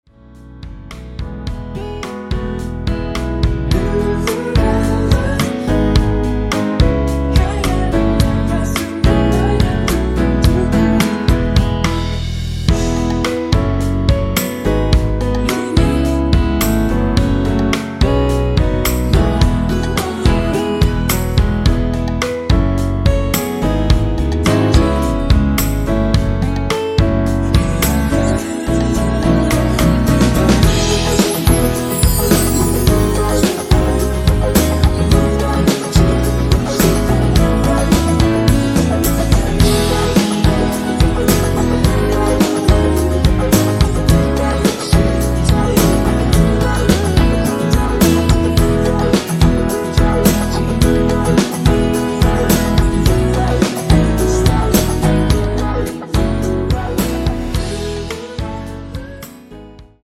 원키에서(-3)내린 코러스 포함된 MR입니다.(미리듣기 확인)
앞부분30초, 뒷부분30초씩 편집해서 올려 드리고 있습니다.
중간에 음이 끈어지고 다시 나오는 이유는